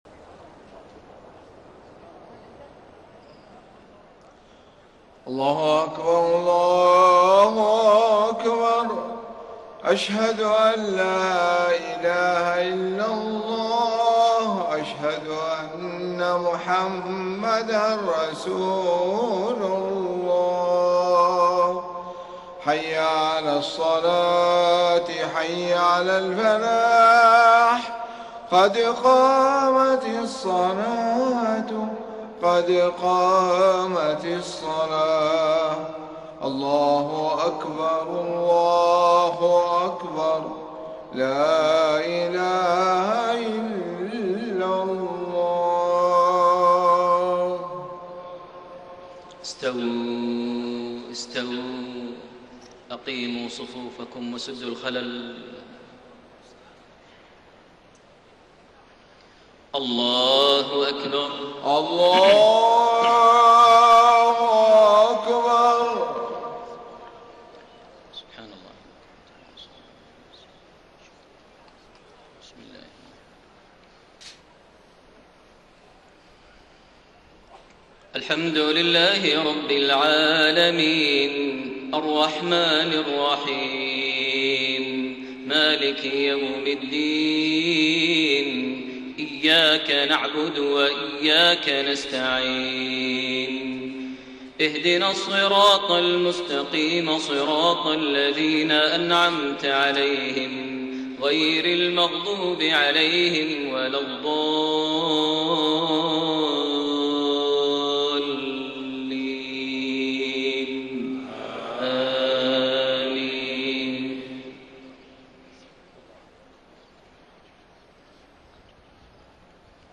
Maghrib prayer from Surah Al-Muminoon > 1433 H > Prayers - Maher Almuaiqly Recitations